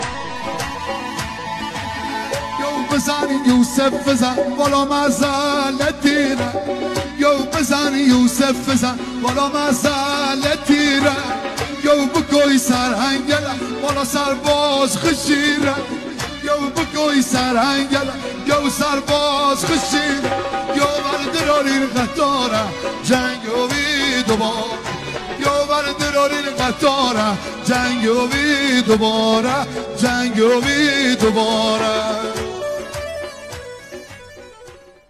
دانلود آهنگ بختیاری